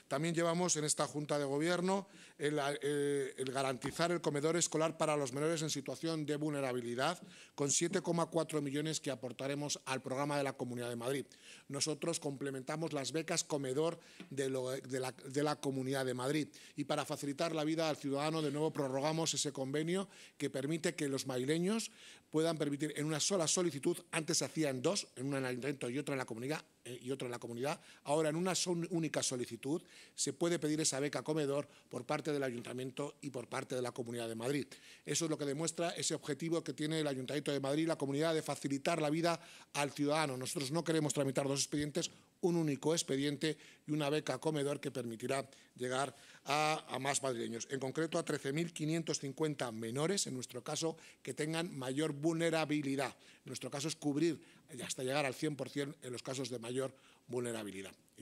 Tal y como ha explicado en rueda de prensa el delegado de Políticas Sociales, Familia e Igualdad, José Fernández, el área que dirige transferirá a la Consejería de Educación, Ciencia y Universidades un total de 7,4 millones de euros para asegurar la cobertura del servicio de comedor durante el curso 2026-2027 a menores de la ciudad en situación de vulnerabilidad que, siendo beneficiarios de la beca comedor regional, no puedan asumir el coste total del menú escolar.